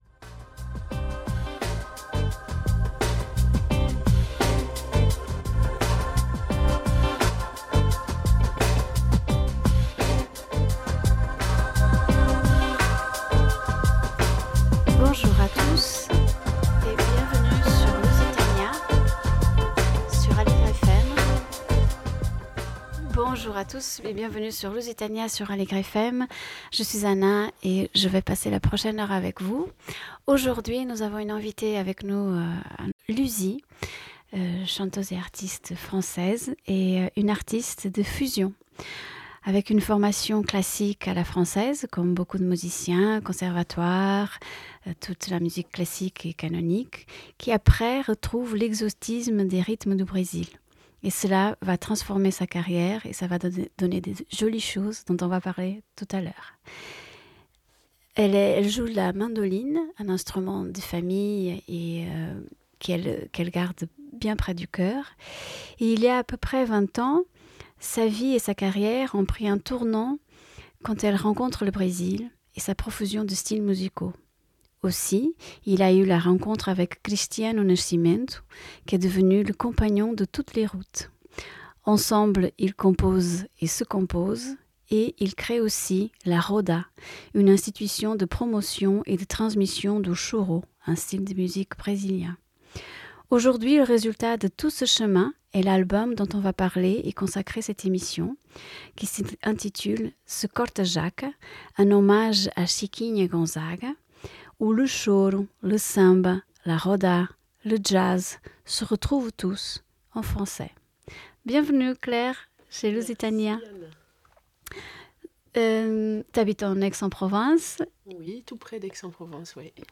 la chanteuse et instrumentiste